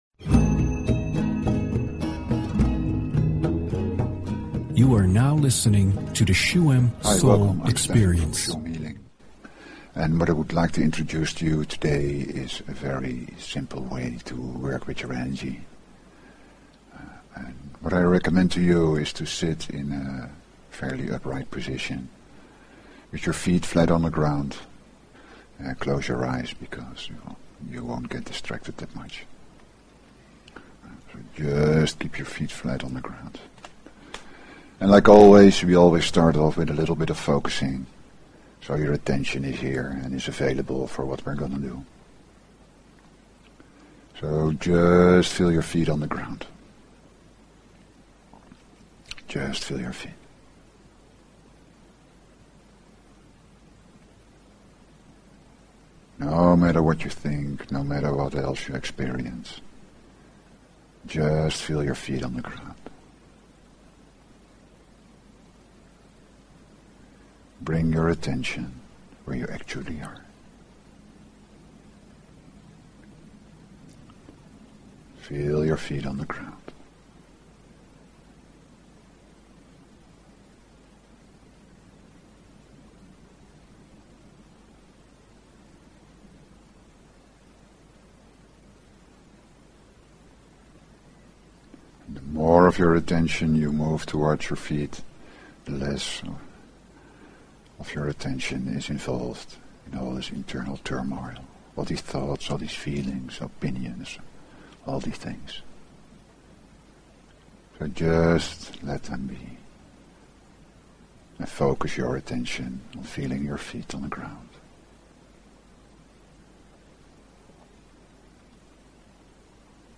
Shuem Soul Experience is a radio show with: